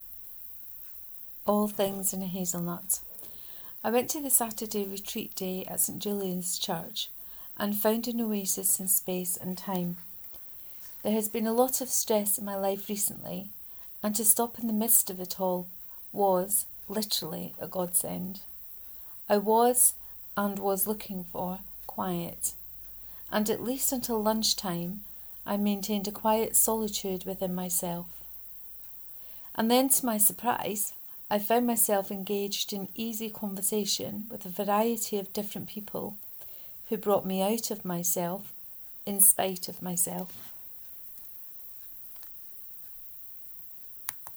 Reading of All things in a hazelnut part 1